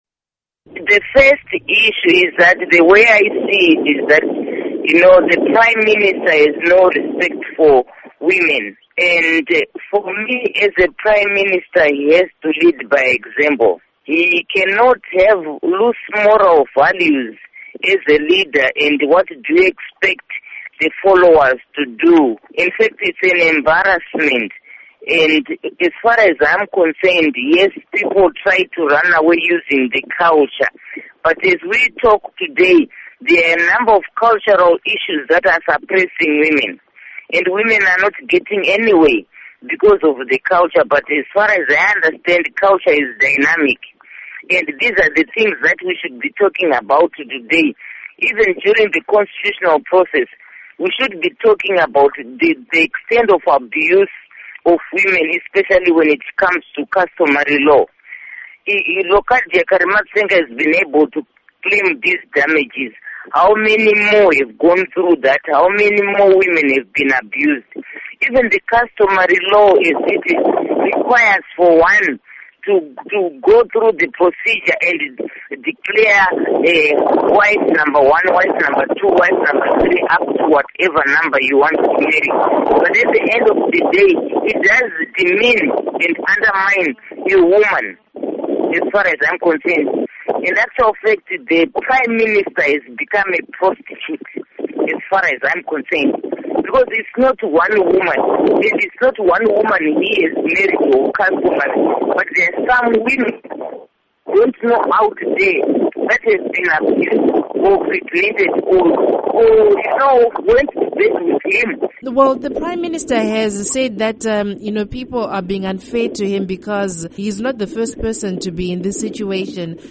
Interview Margaret Dongo